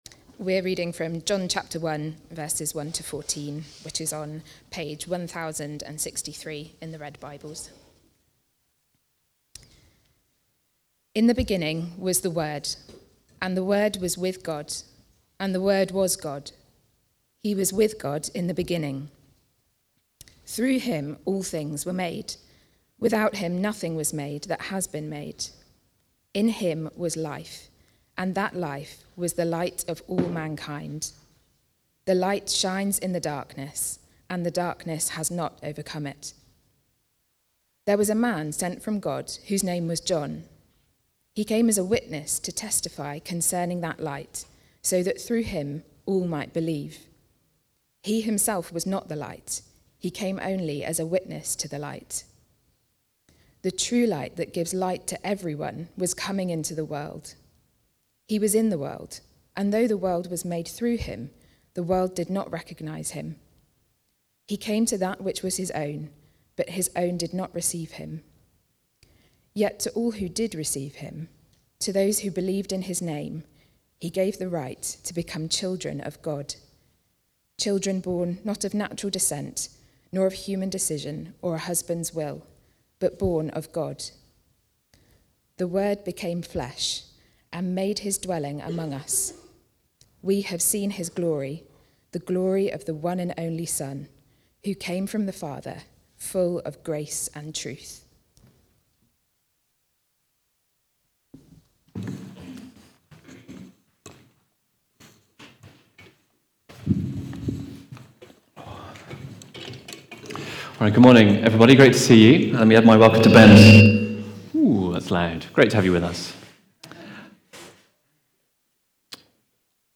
Jesus' Body (John 1:1-14) from the series The Grand Miracle: Reflection on the Incarnation. Recorded at Woodstock Road Baptist Church on 24 November 2024.